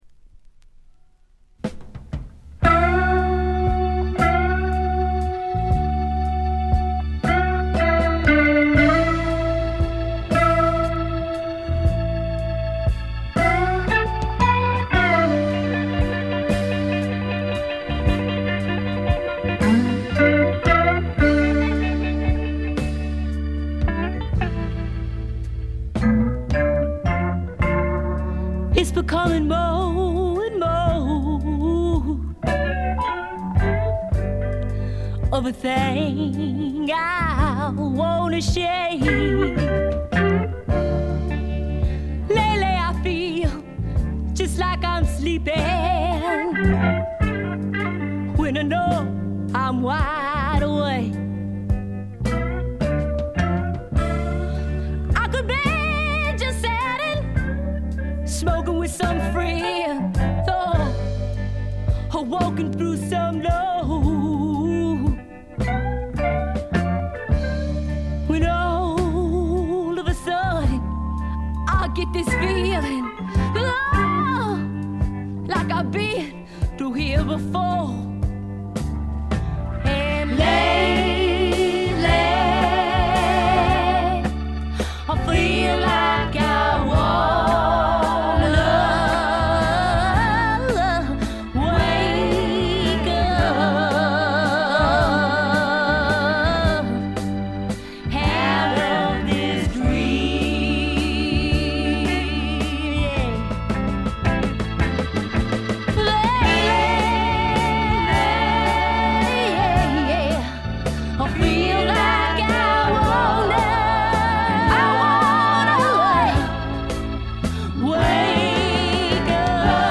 ホーム > レコード：米国 女性SSW / フォーク
ごくわずかなノイズ感のみ。
試聴曲は現品からの取り込み音源です。